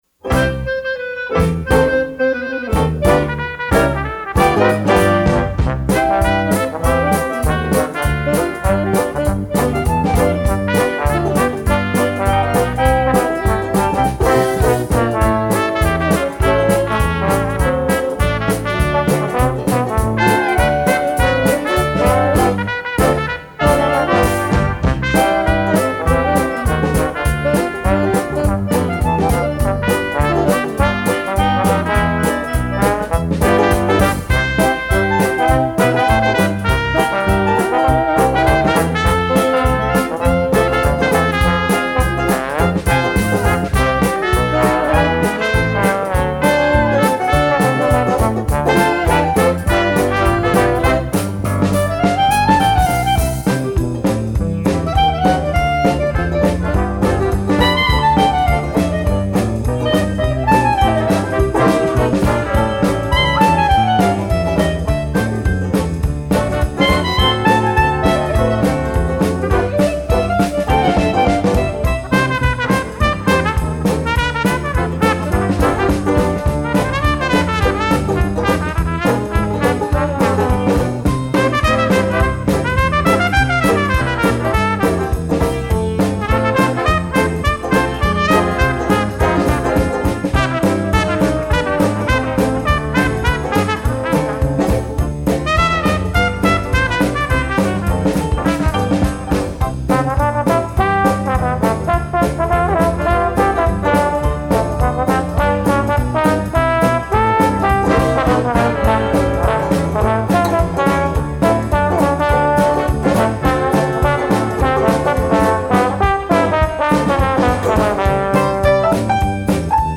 Gattung: Dixieland Combo
Besetzung: Dixieland Combo